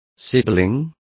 Complete with pronunciation of the translation of sibling.